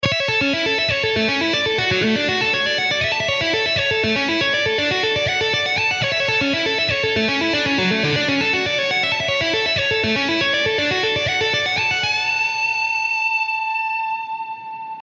Click the following links to view some examples of rock guitar technical studies for intermediate and advanced students.
A (I) and D (IV) Arpeggio Study
A-I-and-D-IV-Arpeggio-Study.mp3